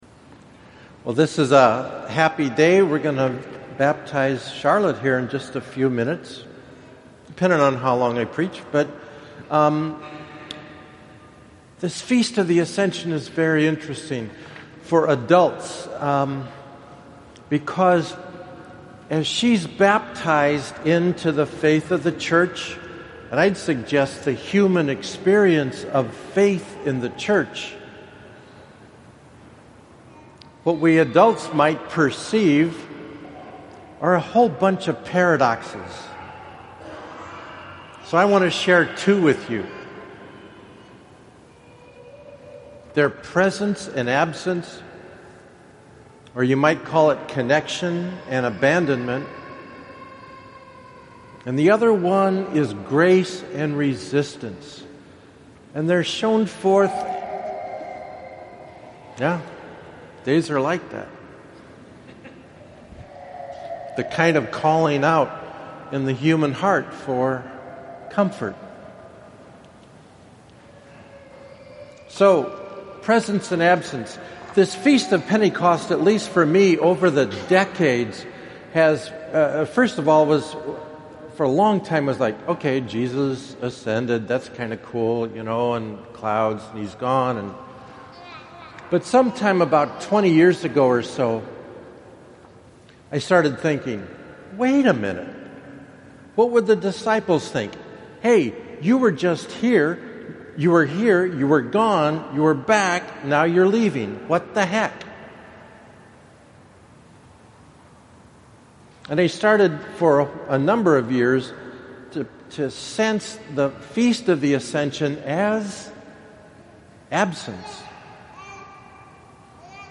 POSTS: Video Commentaries & Homilies (Audio)